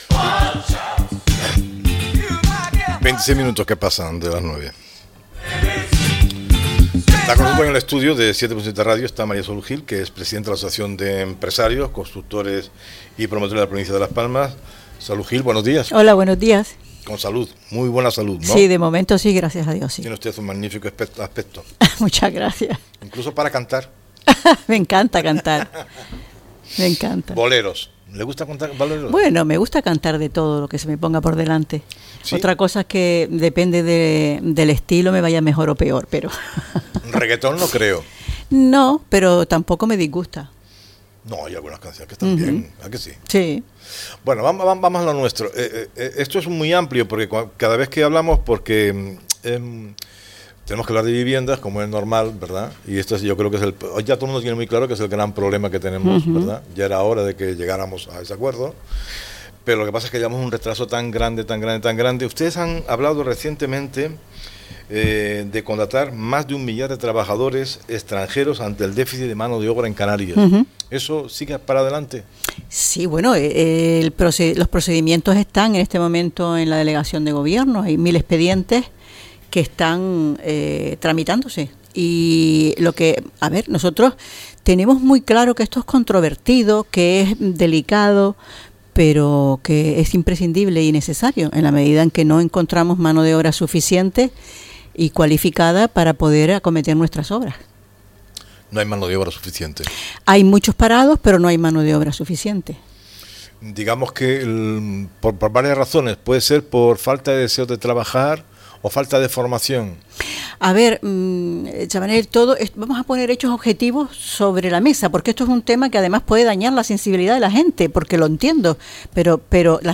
fue entrevistada por el programa radiofónico El Espejo Canario, en el que habló de la falta de mano de obra que atraviesa el sector de la construcción en Canarias.